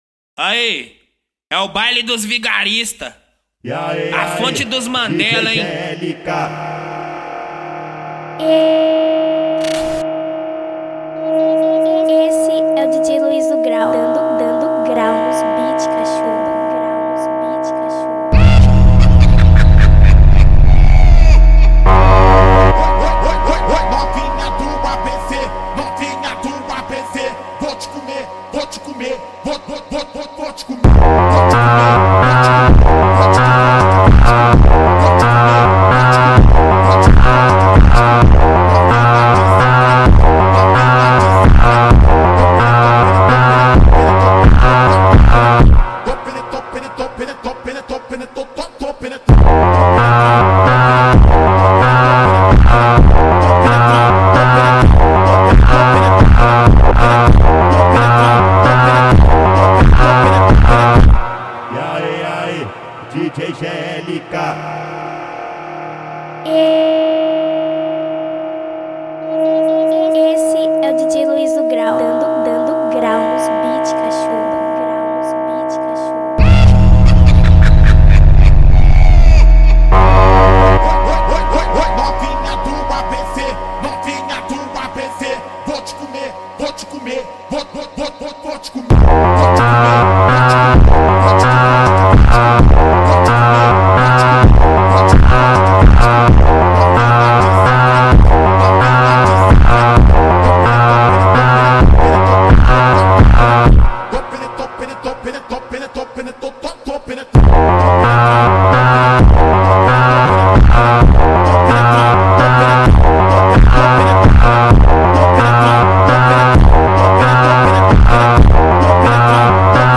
2024-06-11 16:13:22 Gênero: Phonk Views